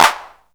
DrClap14.wav